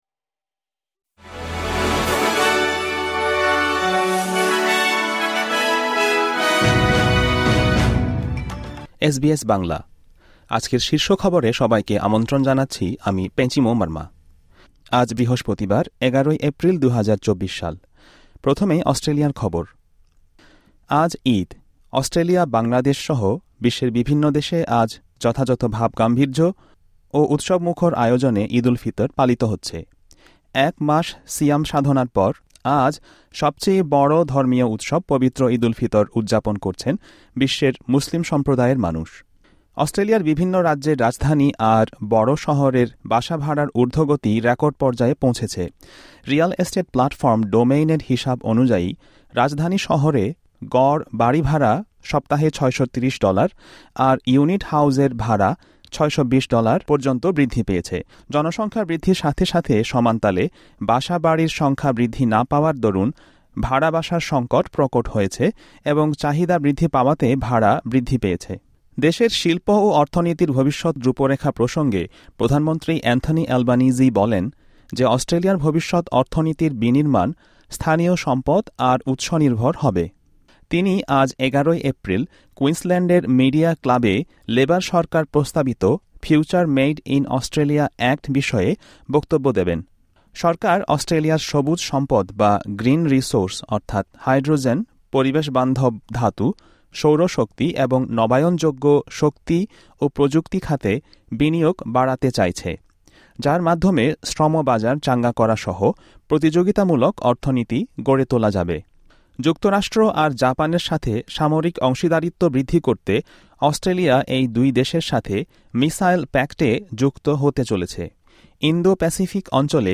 এসবিএস বাংলা শীর্ষ খবর: ১১ এপ্রিল, ২০২৪